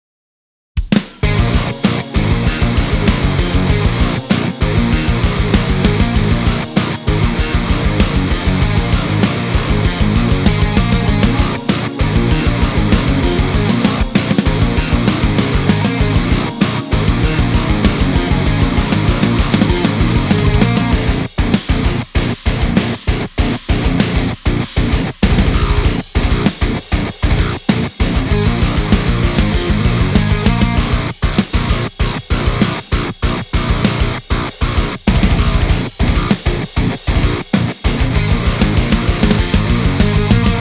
Сиплый хай-гейн звук
Народ, есть вот такой несведённый мультитрек, вроде бы звучит неплохо, но звук ритм гитар какой-то сиплый, по-другому не сказать, подозреваю, что это вопрос к формированию исходного тембра, что может влиять на сигнал таким образом? Нарулено на Bias Amp с грелкой TS-999, импульсы Энгла, так что я могу влиять на любой элемент цепи, но что крутить не могу понять.
Ещё, судя по всему, гитара записана на не очень свежих струнах, но это обычно не влияет так.